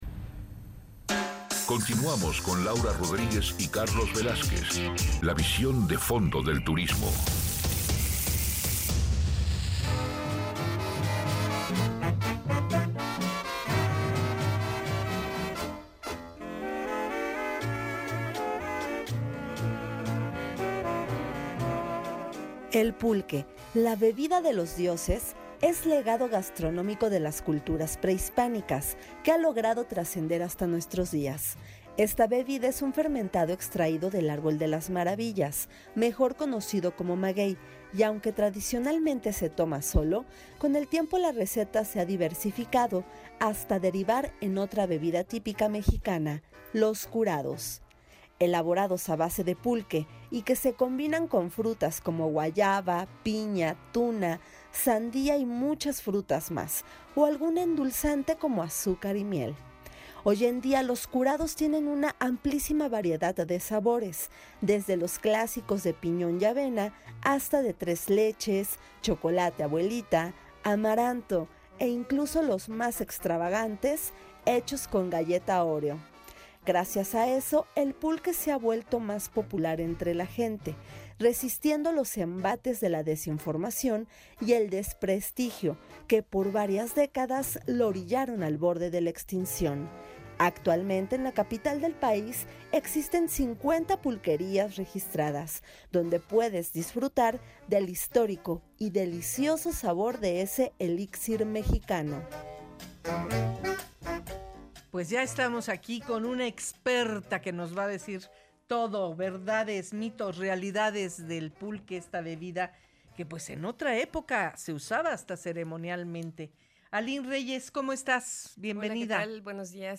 PODCAST-MITOS-Y-REALIDADES-DEL-PULQUE.mp3